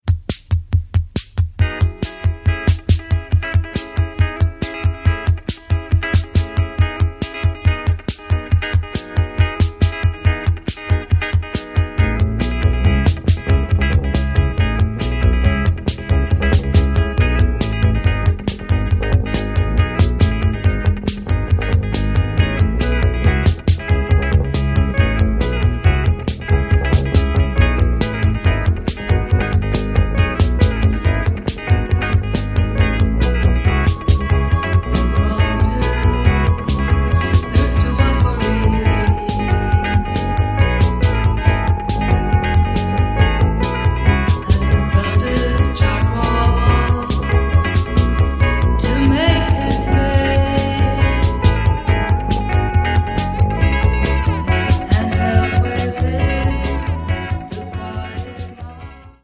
Electronix Wave Ambient